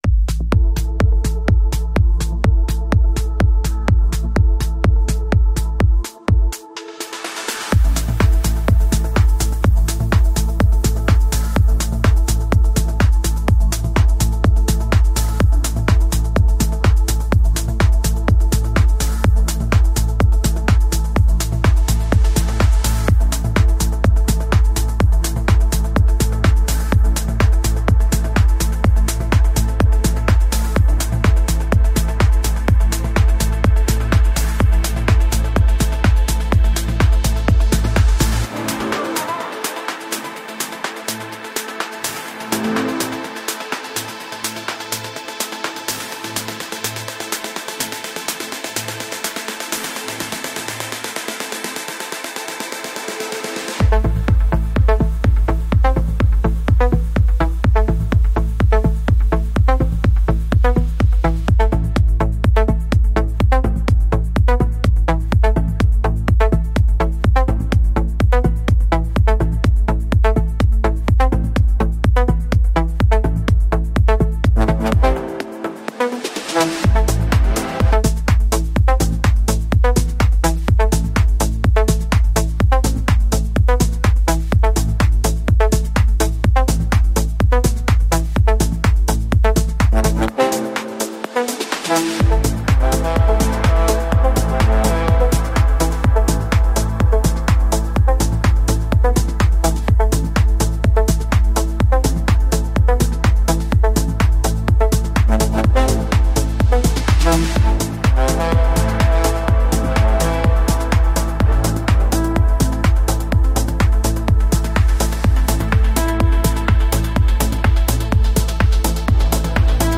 Showing all tracks in the "Techno" category.